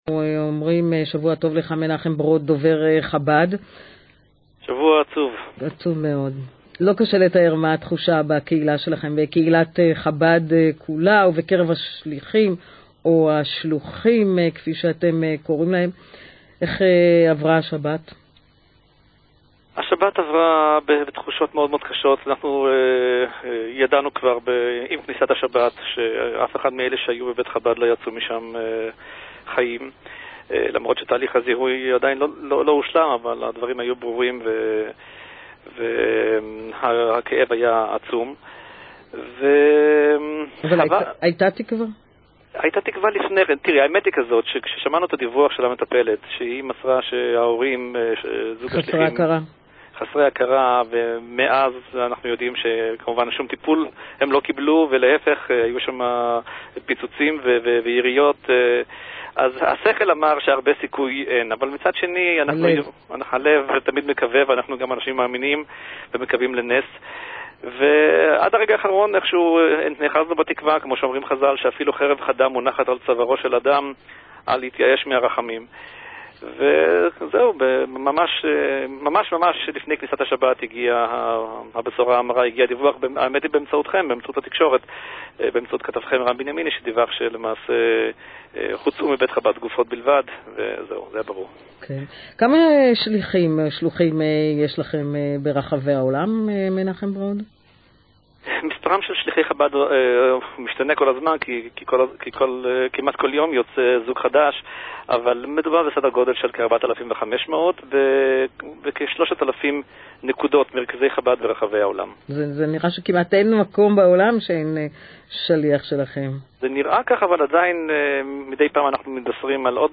בראיון ביומן קול ישראל במוצ"ש